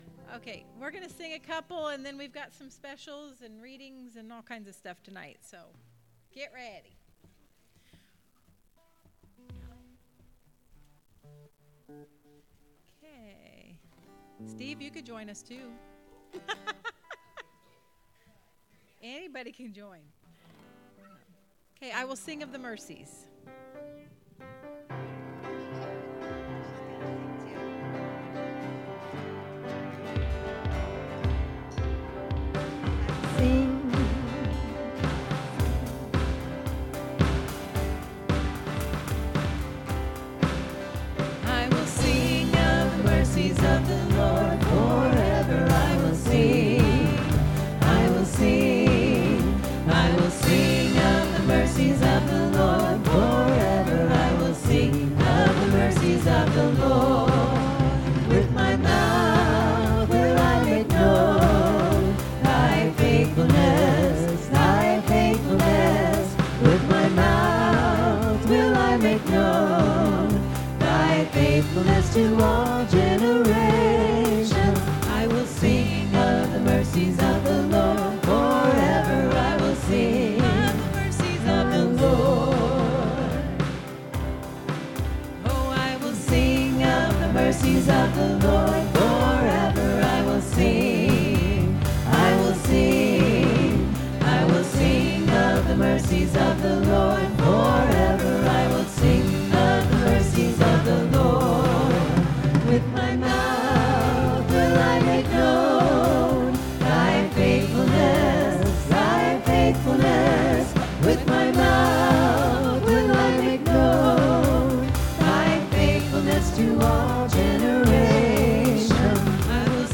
5th Sunday Sing